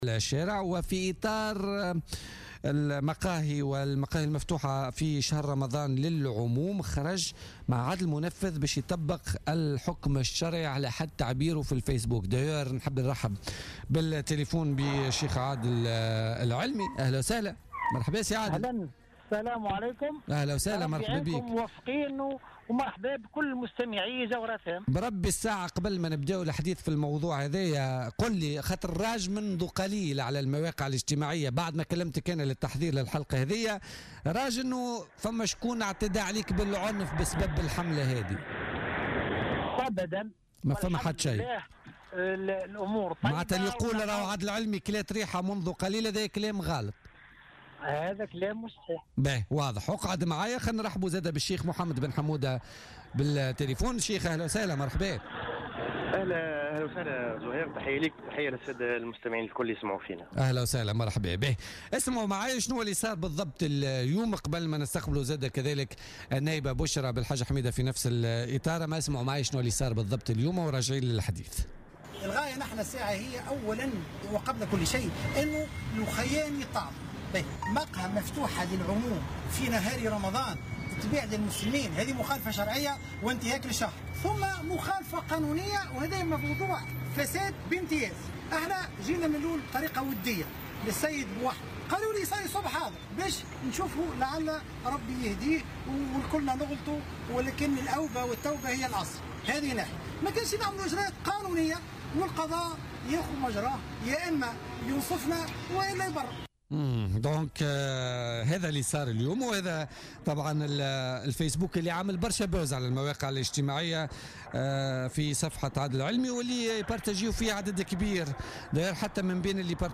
أكد والي سوسة عادل الشليوي ضيف بولتيكا اليوم الأربعاء 31 ماي 2016 أنه تسلم المنصب و وجد أمامه ملفات حارقة سيعمل على حلها وأهمها الوضع البيئي والأمني في سوسة وجهة السياح من مختلف أنحاء العالم.